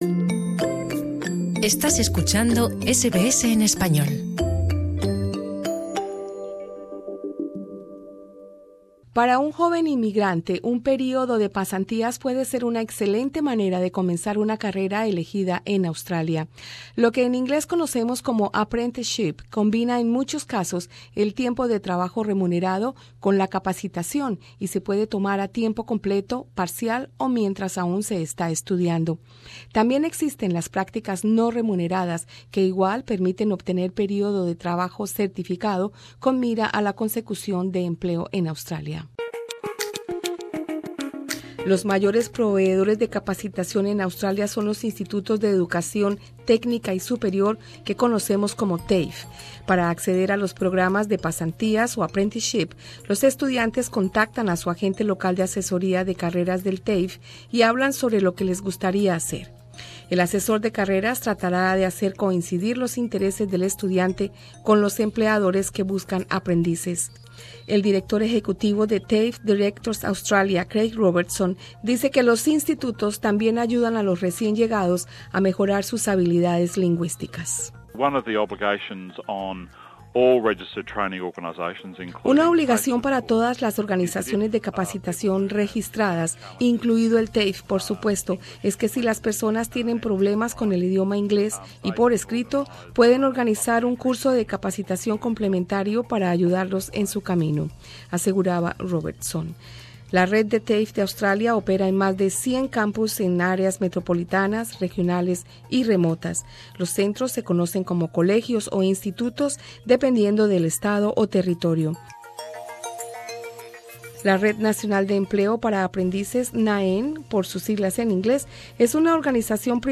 Escucha arriba en nuestro podcast la información sobre "apprenticeship" en Australia y la entrevista